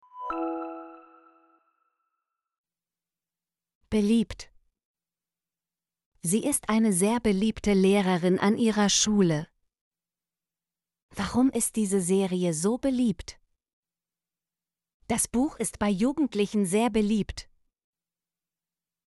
beliebt - Example Sentences & Pronunciation, German Frequency List